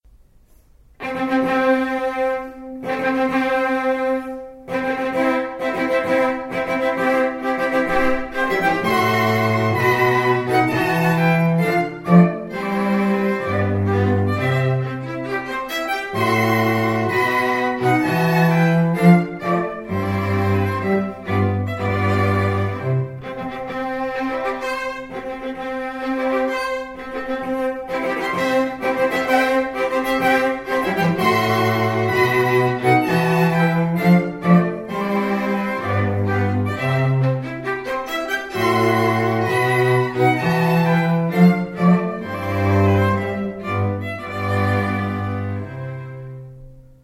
• Fully acoustic - can play anywhere